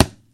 低音范围击中" 4
描述：塑料物体撞击
标签： 低音 吊杆 金属 对象 捶击
声道立体声